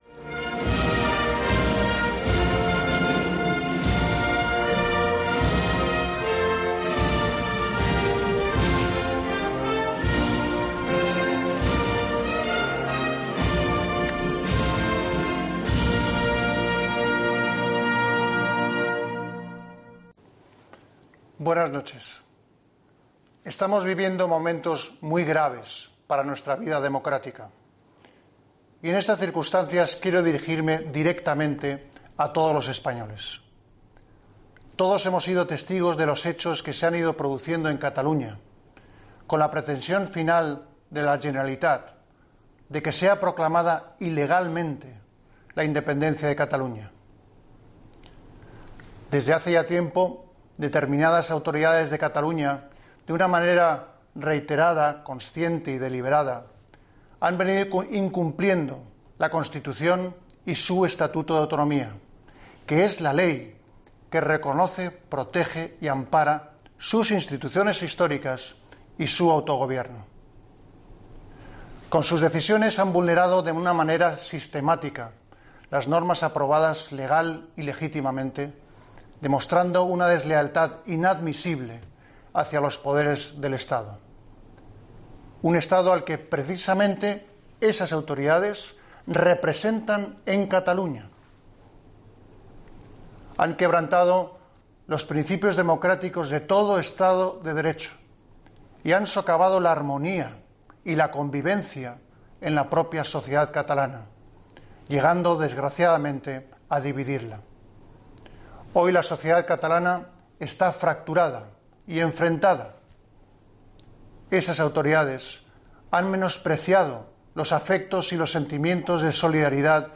En un excepcional mensaje institucional a todos los españoles grabado en su despacho del Palacio de la Zarzuela y emitido por televisión a las 21:00 horas, Felipe VI ha subrayado que "determinadas autoridades de Cataluña" han "quebrantado los principios democráticos de todo Estado de derecho".